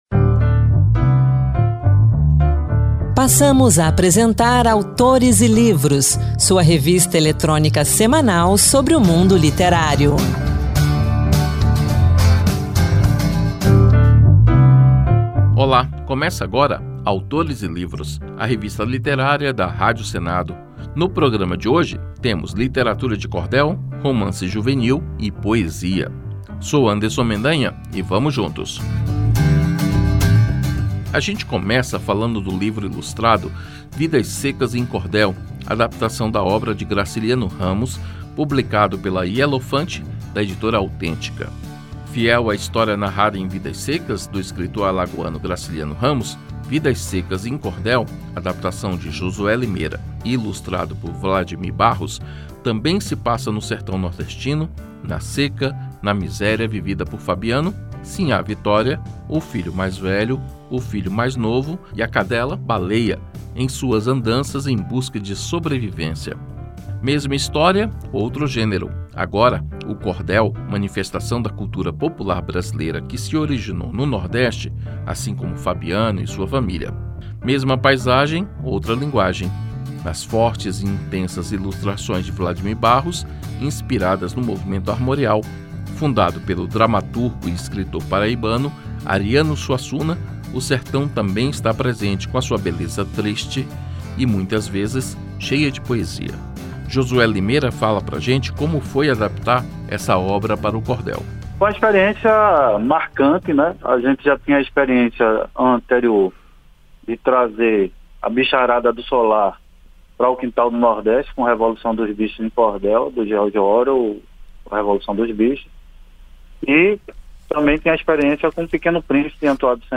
No Autores e Livros dessa semana uma conversa